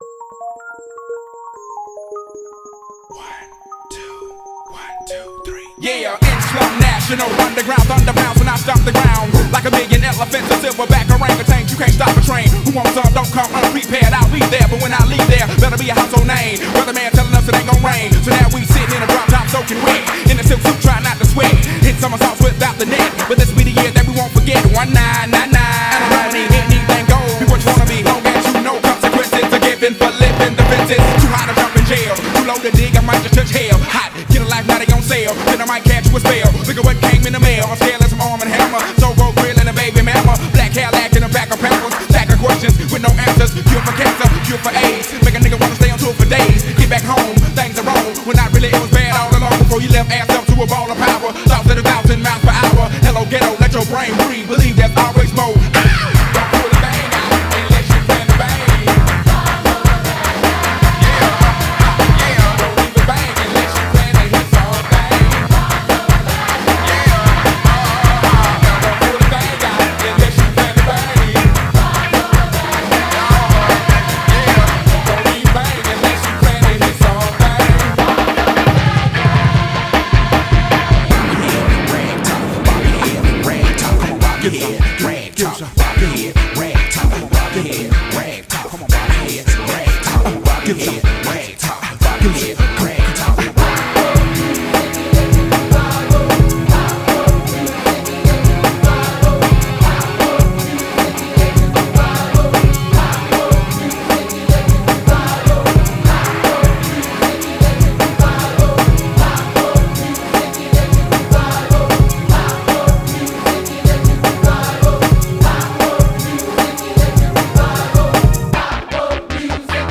BPM154
Audio QualityLine Out